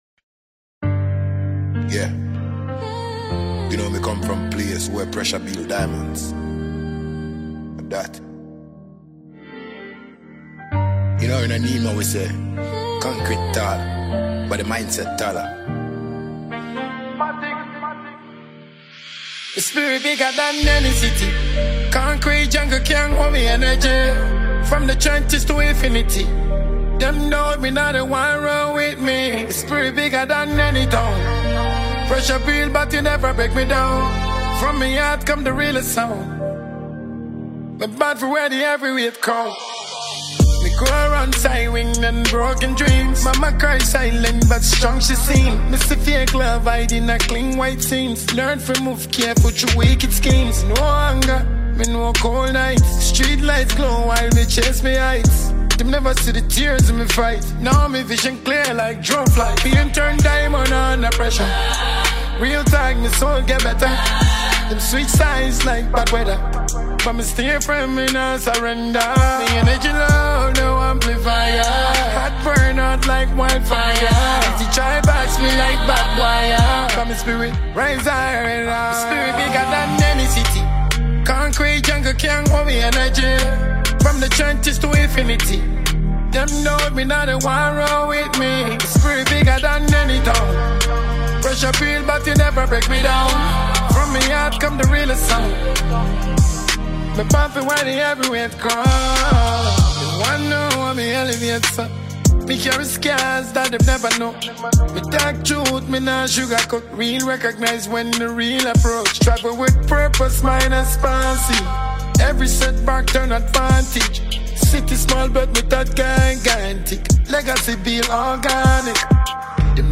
a Ghanaian afrobeats dancehall musician and songwriter.